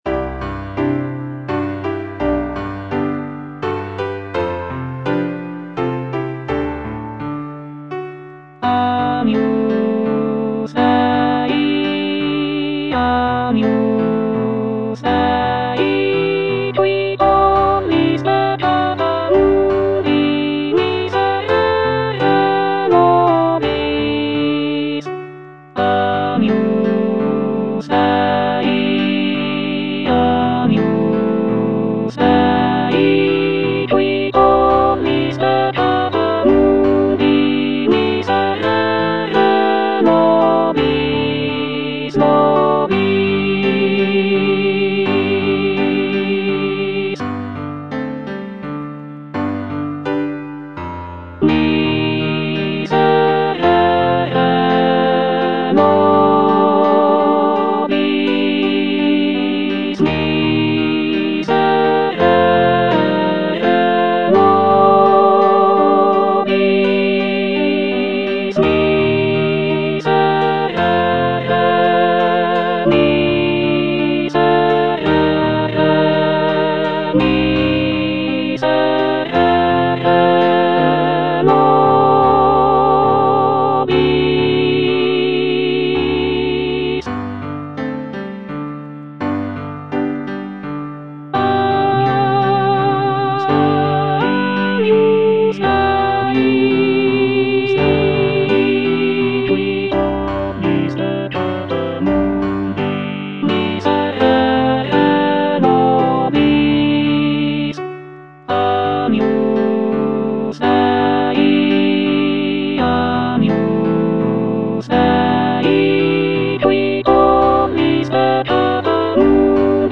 Alto (Emphasised voice and other voices)